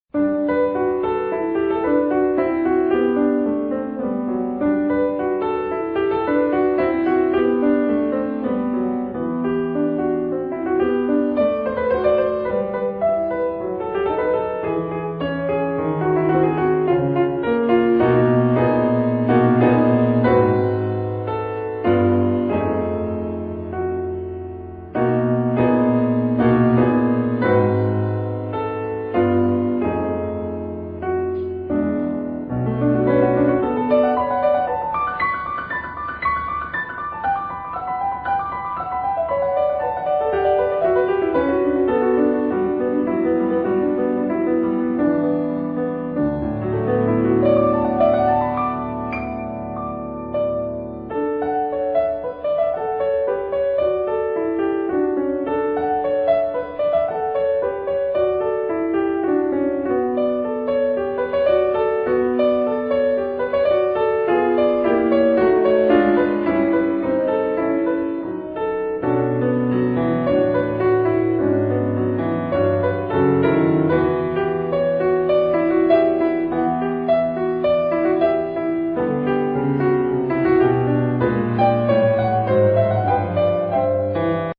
全為室內樂作品，且風格多變，
但多帶著典雅的貴族氣息，與些許沒落的哀愁。